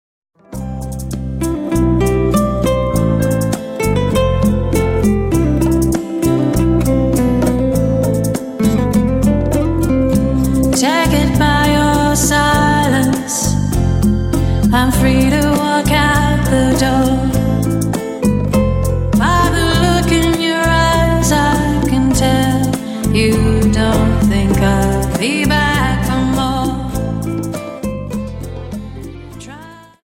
Dance: Rumba